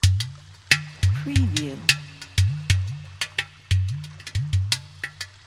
سمپل ریتم کوزه
• سرعت: 90-130
• ریتم: 3/4-4/4-6/8
ساز کوزه از خانوادهٔ سازهای کوبه‌ای و دسته‌ای می‌باشد.
در این پکیج ۳۳۰ ریتم در میزان ها ۳/۴ – ۴/۴ و ۶/۸ در سرعت های ۹۰ و ۱۳۰ قرار دارد
تمام سمپل ها با بالاترین کیفیت از ساز زنده کوزه گرفته شده است.
demo-kuzeh.mp3